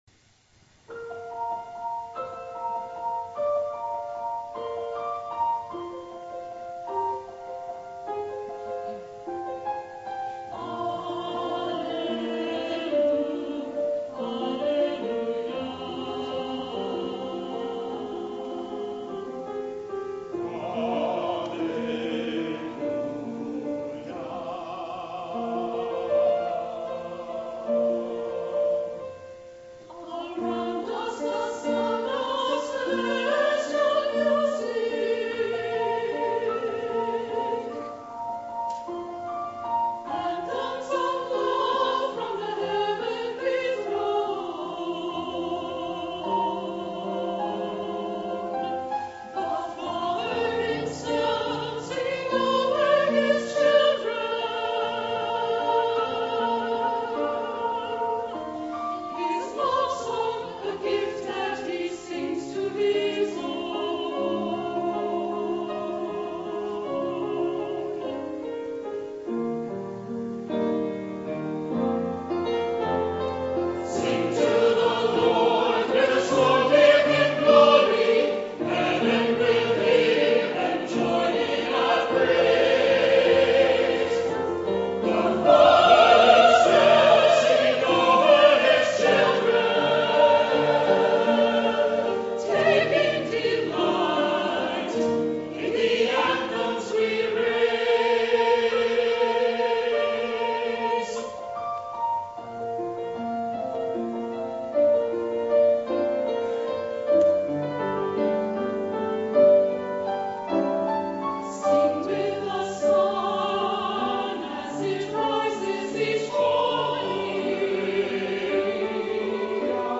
The Second Reformed Chancel Choir sings "Anthems of Love" by Dan Forrest